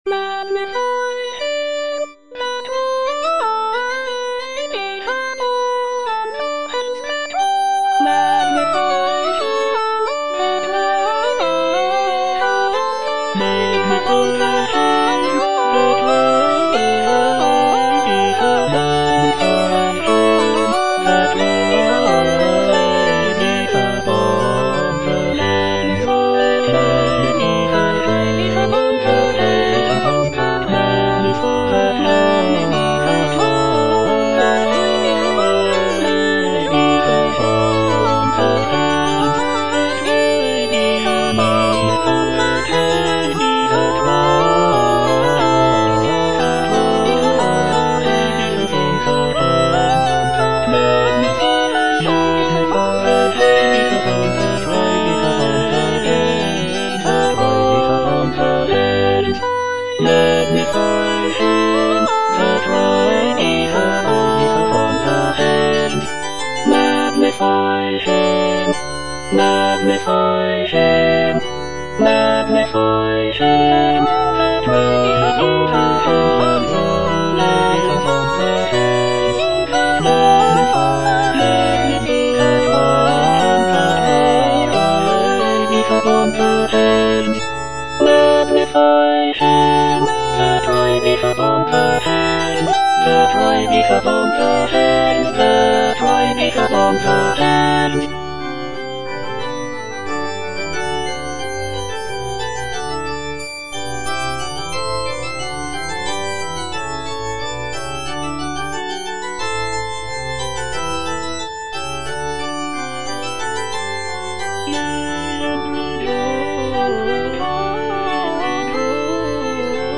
T. LINLEY - LET GOD ARISE Magnify him (All voices) Ads stop: auto-stop
"Let God arise" is a sacred choral work composed by Thomas Linley the younger, an English composer and conductor. Written in the Baroque style, the piece features a majestic and uplifting melody that conveys a sense of reverence and awe. The text of the work is taken from Psalm 68, which speaks of God's power and glory.